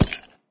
icegrass3.ogg